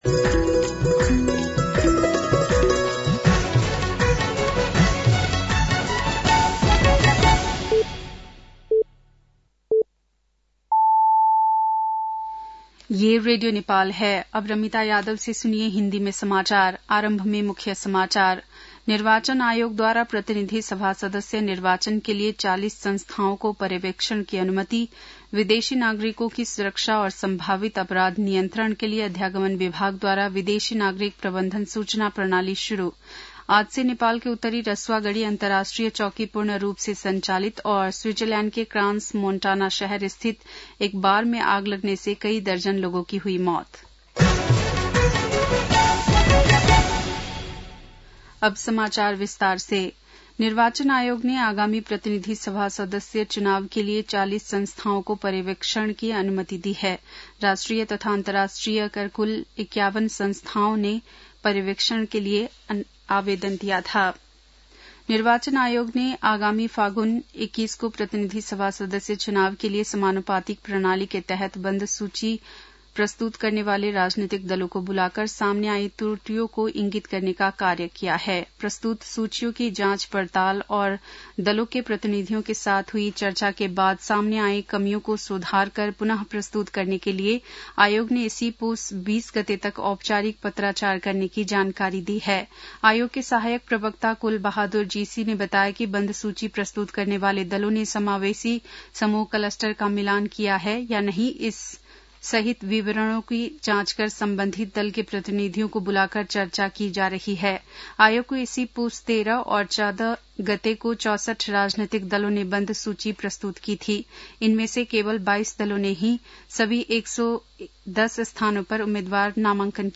बेलुकी १० बजेको हिन्दी समाचार : १७ पुष , २०८२